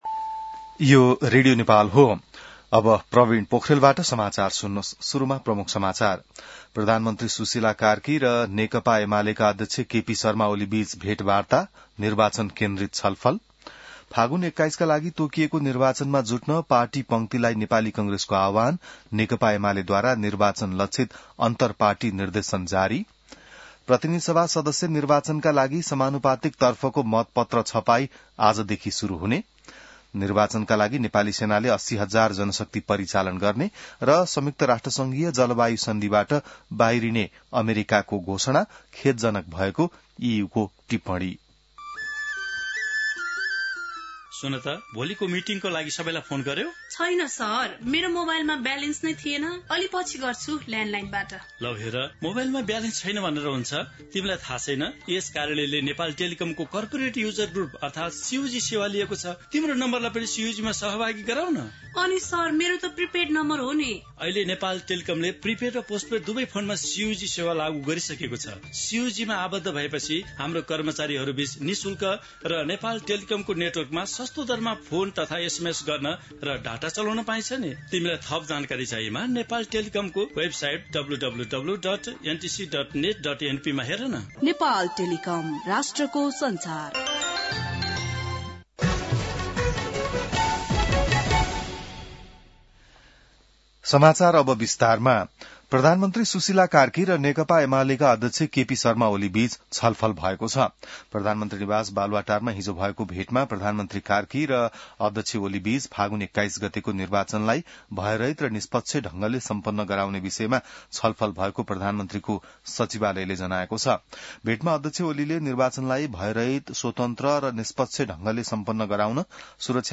बिहान ७ बजेको नेपाली समाचार : २५ पुष , २०८२